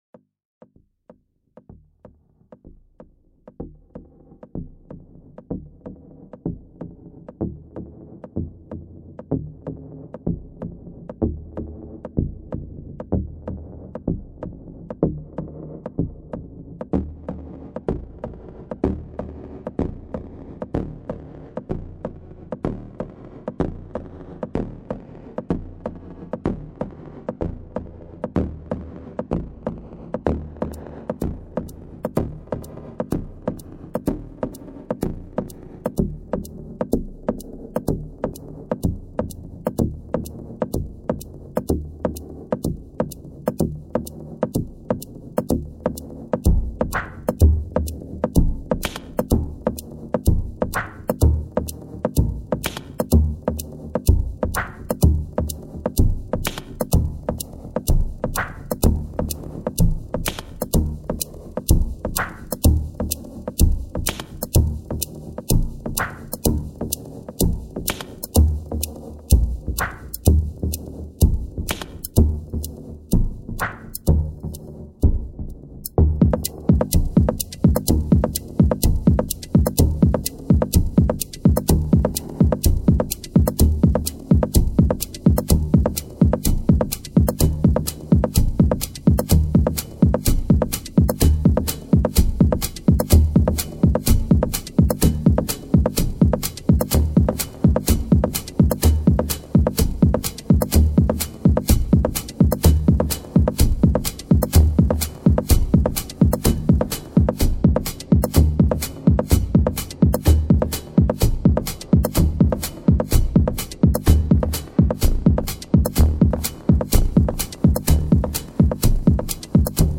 genre: dub minimal techno
style: trax